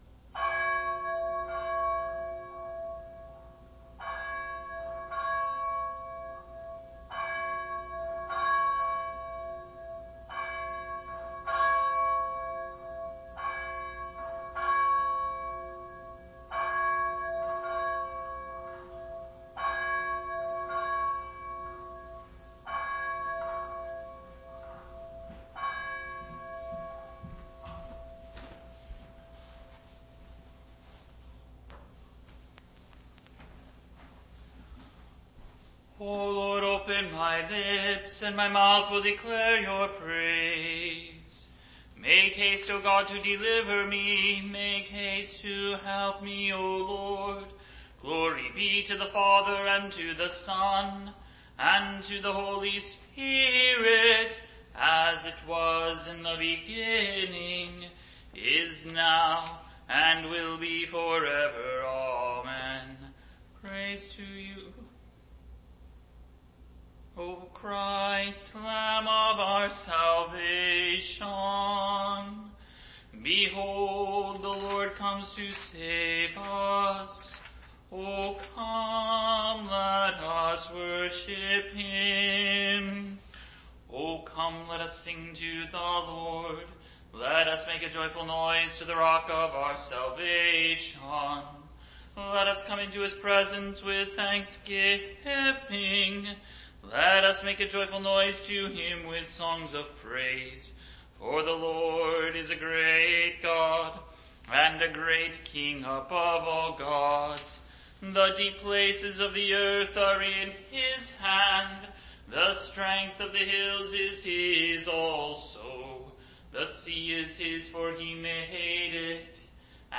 2023-03-01-Matins.mp3